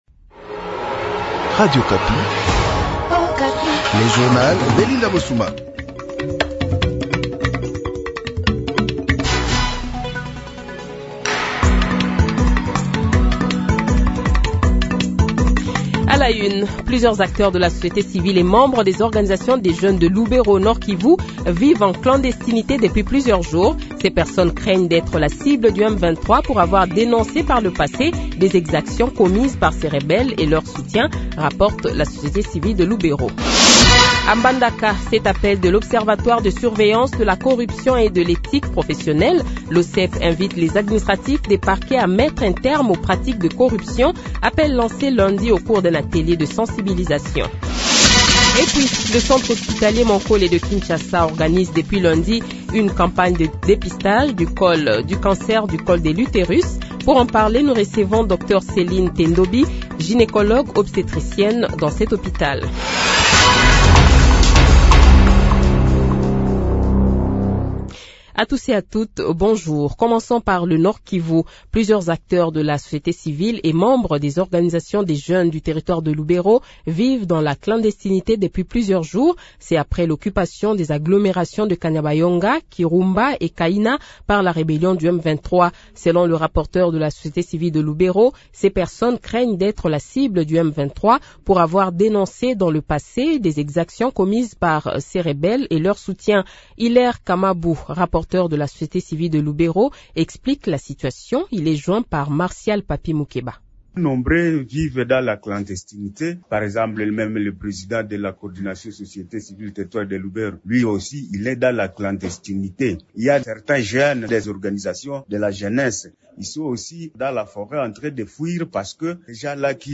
Le Journal de 8h, 03 Juillet 2014 :